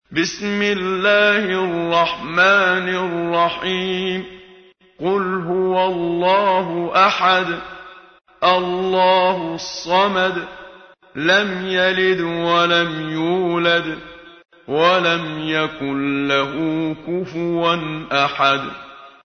تلاوت سوره توحید با صدای محمد صدیق منشاوی